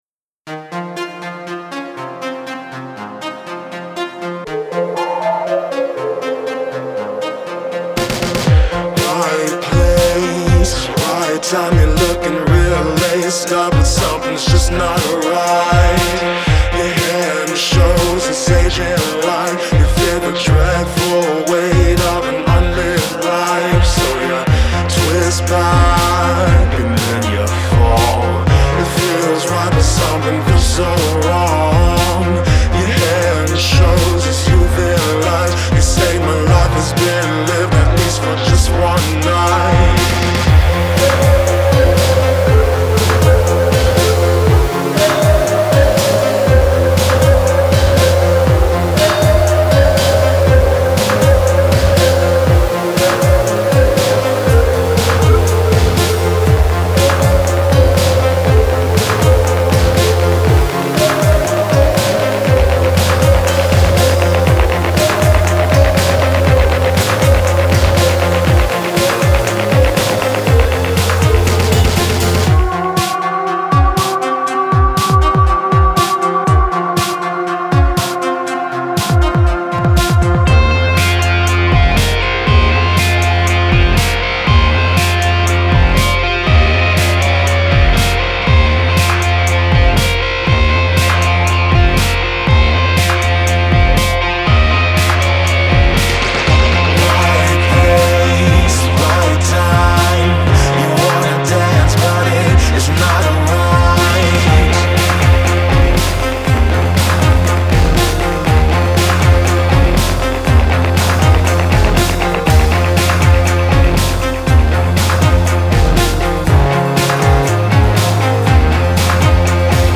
R&B-ish electro-pop
which is as creepy as it is catchy